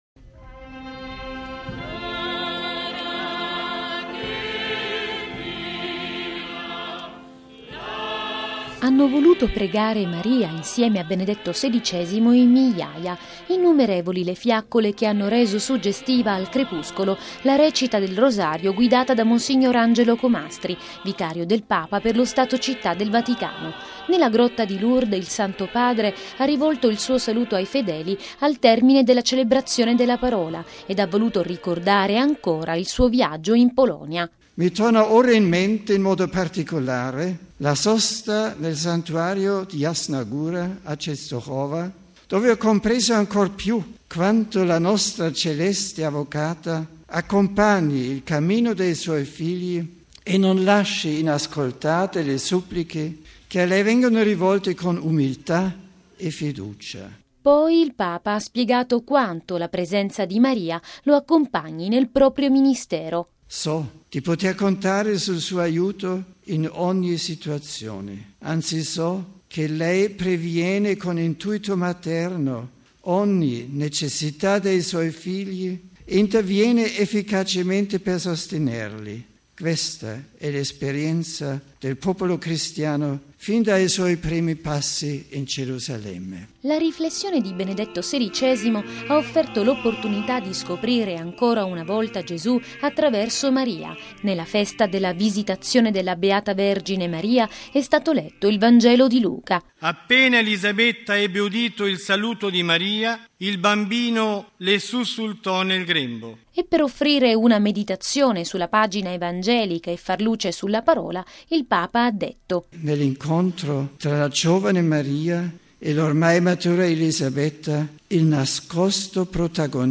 (01 giugno 2006 - RV) La conclusione del mese dedicato a Maria è stata celebrata ieri sera con la recita del Rosario lungo i viali dei Giardini Vaticani. Al termine della preghiera mariana si è unito ai fedeli anche Benedetto XVI che prima di impartire la sua benedizione ha offerto una riflessione su Maria.